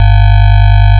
Sample and hold signal from A/D